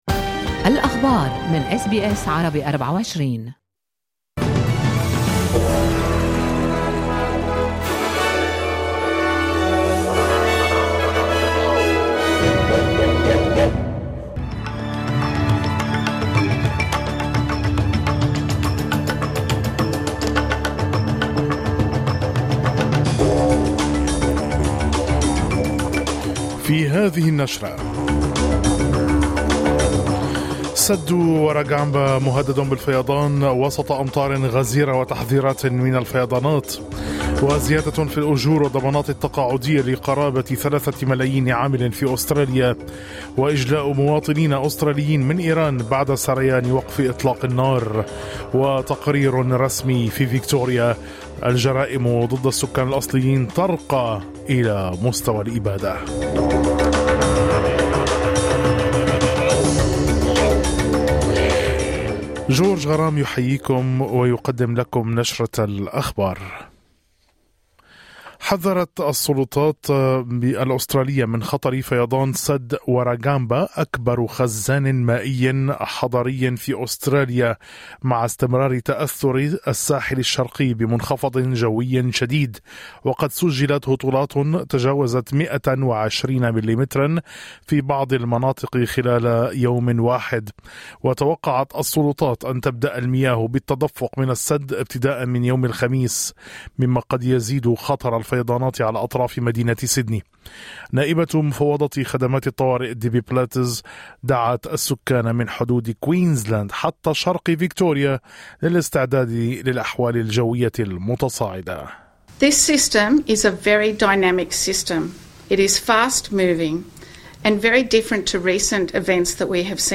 نشرة الأخبار